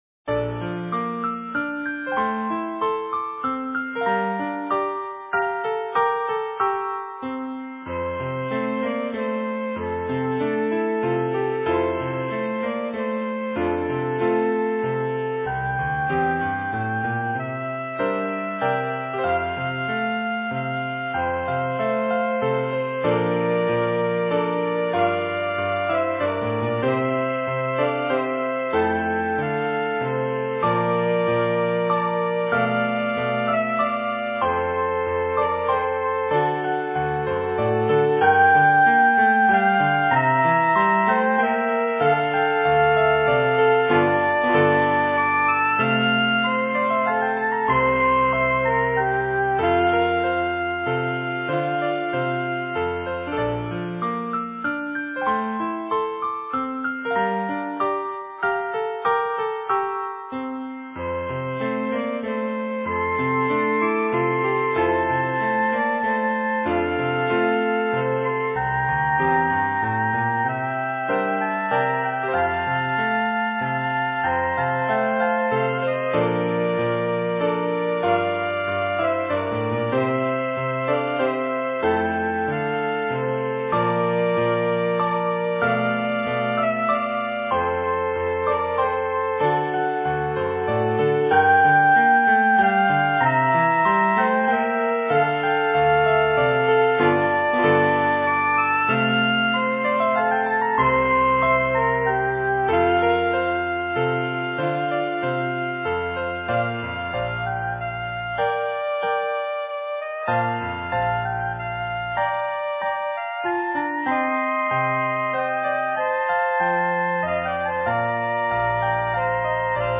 Voicing: Saxophone Duet